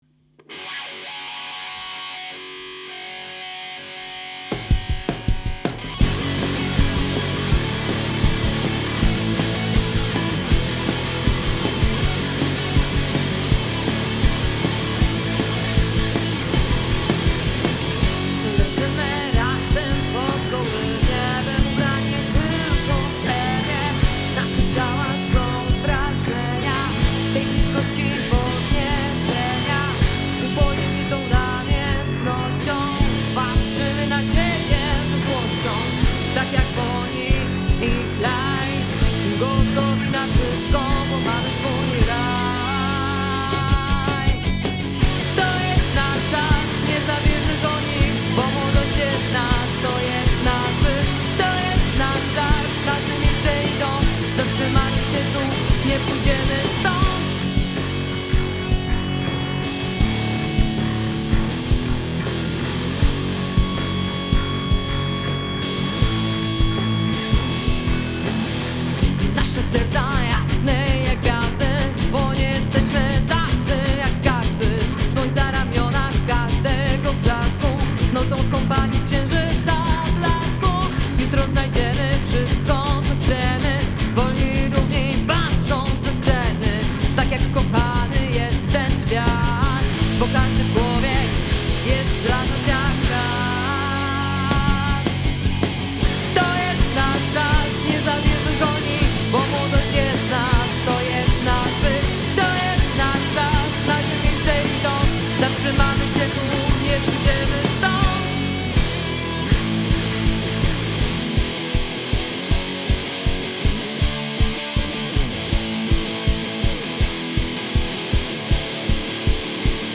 Gatunek: Rock/Hard Rock/Metal